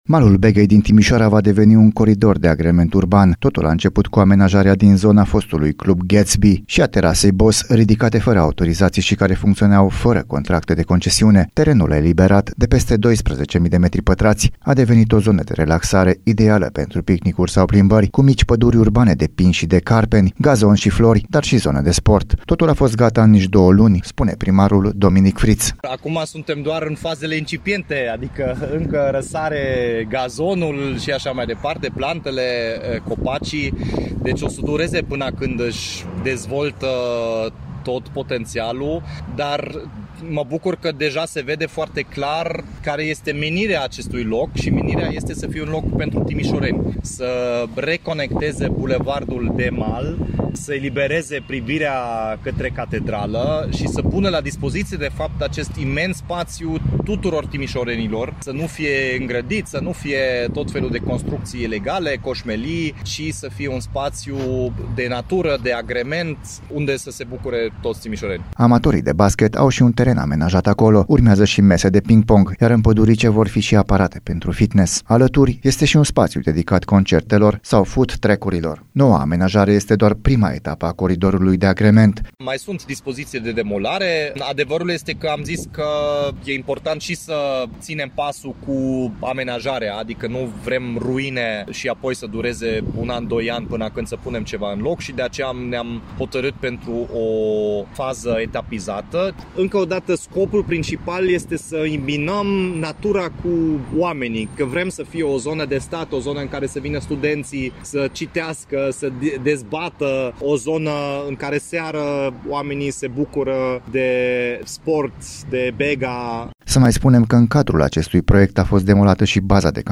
Totul a fost gata în două luni, spune primarul Dominic Fritz.